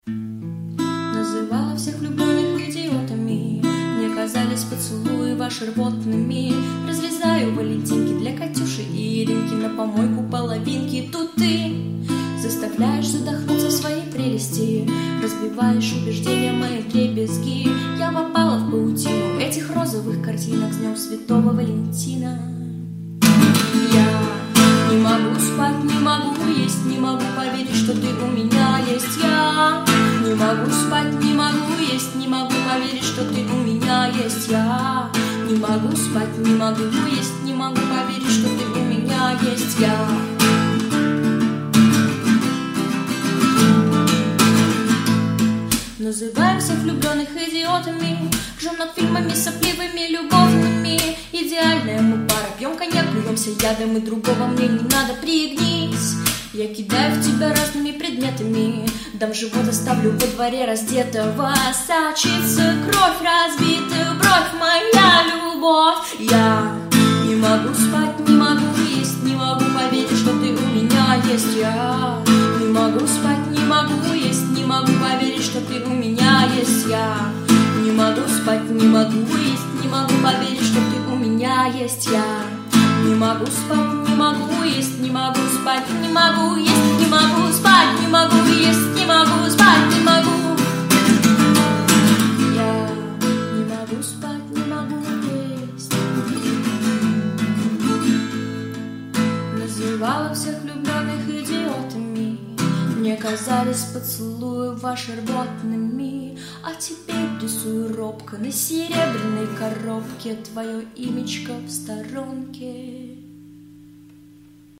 под гитару